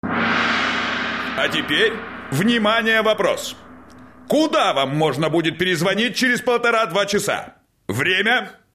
** Приветствующие сообщения для автоответчика **